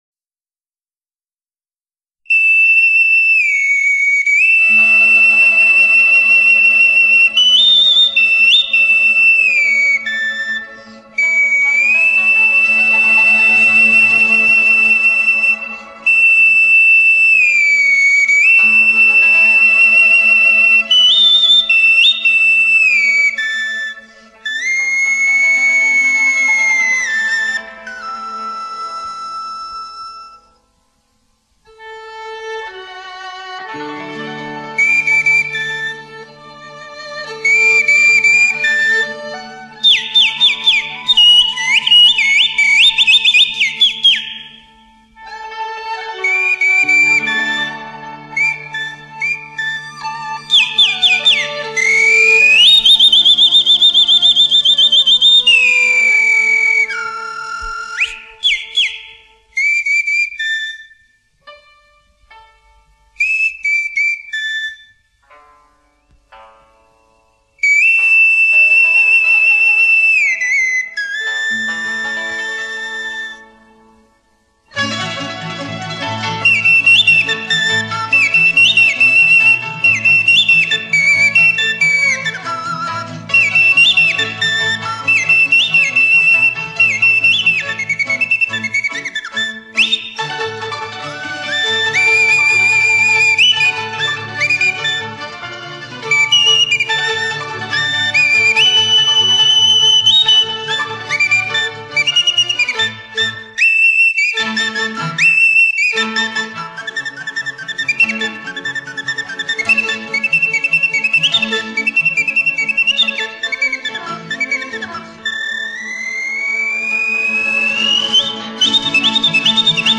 口笛